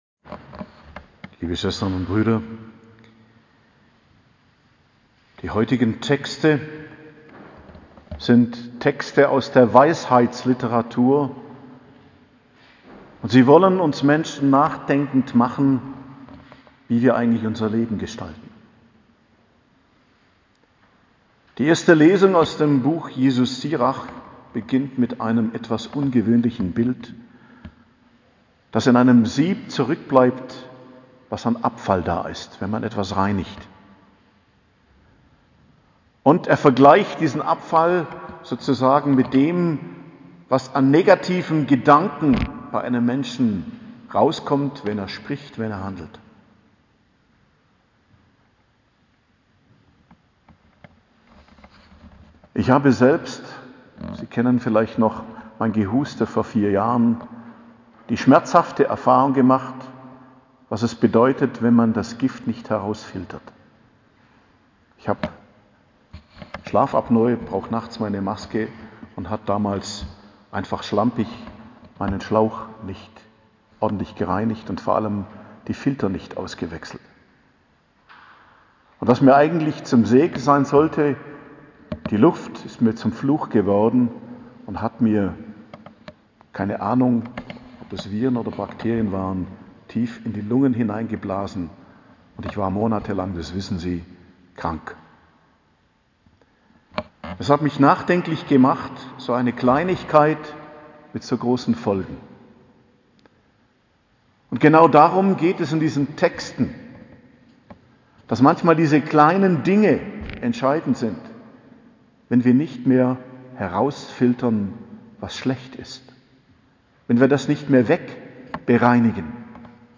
Predigt zum 8. Sonntag im Jahreskreis, 27.02.2022